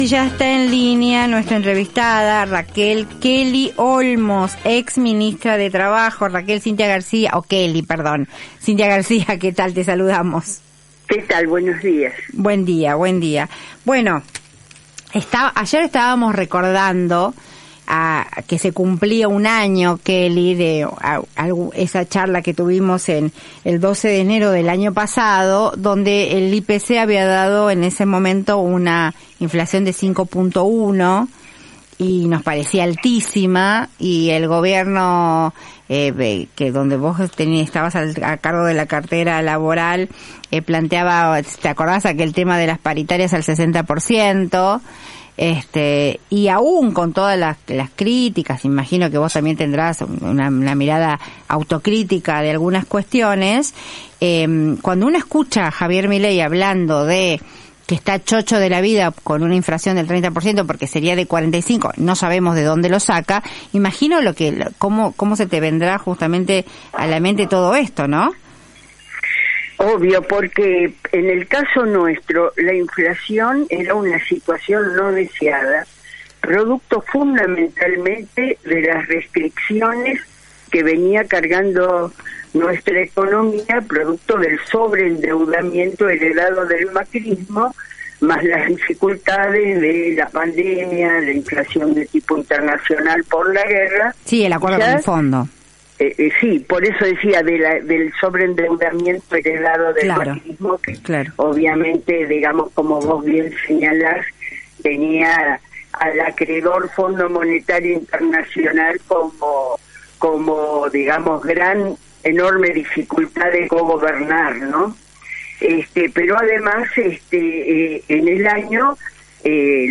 La exministra de Trabajo aseguró que, a diferencia de la gestión de Alberto Fernández, el Presidente de Javier Milei tiene como un objetivo claro y deseado la alta inflación para poder licuar los ingresos de los trabajadores, romper el poder de consumo y promover un plan dolarizador. En diálogo con La García por AM750.